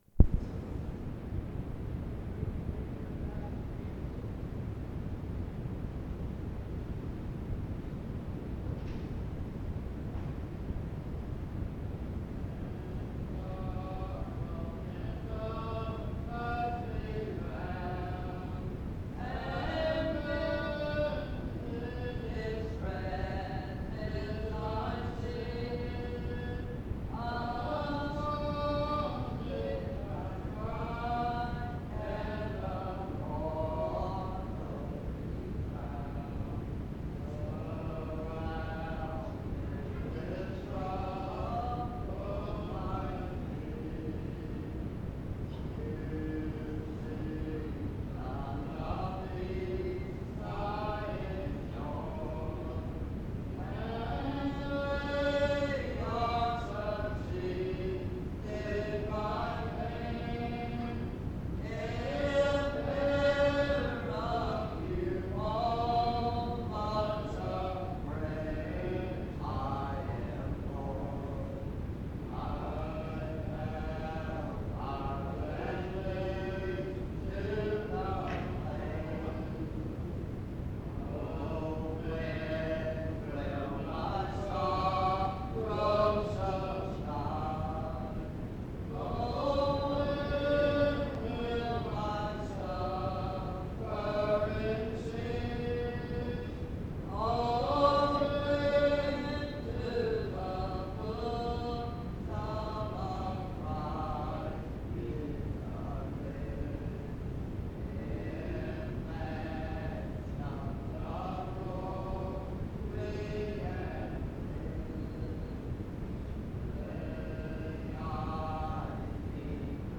Em Collection: Reidsville/Lindsey Street Primitive Baptist Church audio recordings Miniatura Título Data de carga Acesso Ações PBHLA-ACC.001_063-B-01.wav 2026-02-12 Baixar PBHLA-ACC.001_063-A-01.wav 2026-02-12 Baixar